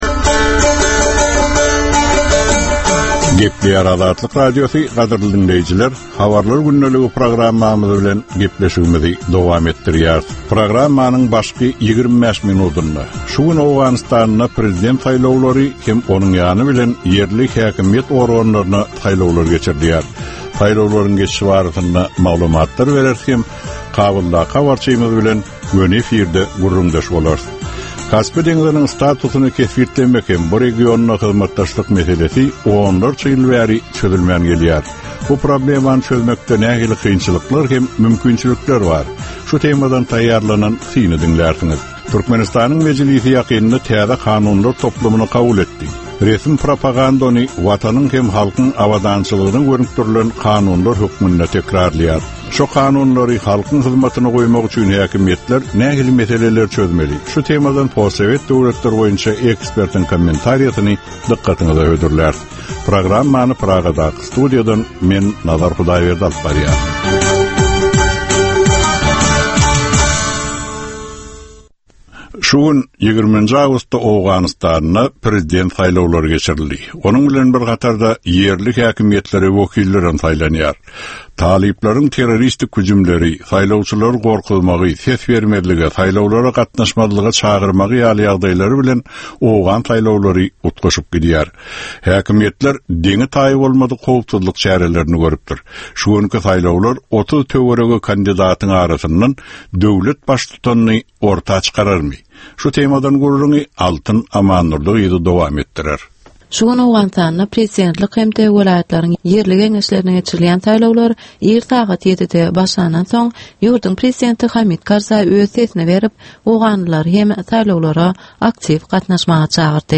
Türkmenistandaky we halkara arenasyndaky sonky möhüm wakalar we meseleler barada ýörite informasion-analitiki programma. Bu programmada sonky möhüm wakalar we meseleler barada ginisleýin maglumatlar, analizler, synlar, makalalar, söhbetdeslikler, reportažlar, kommentariýalar we diskussiýalar berilýär.